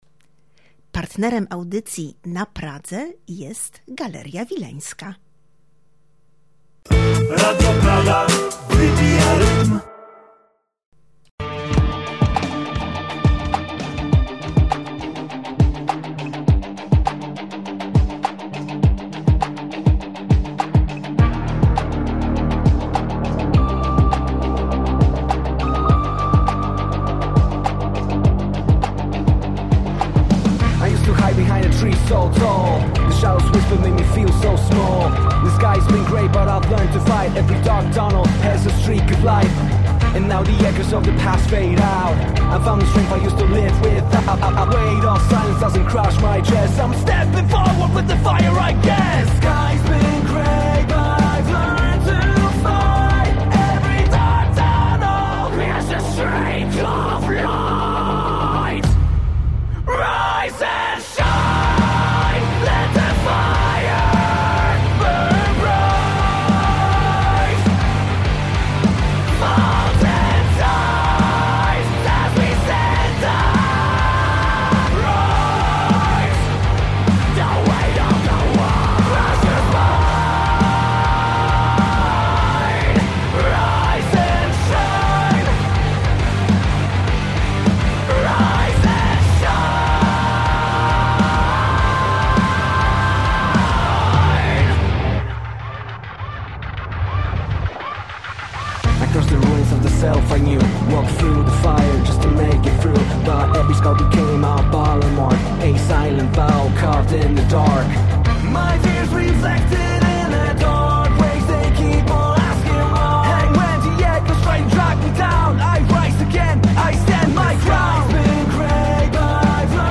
W materiale także muzyka zespołu.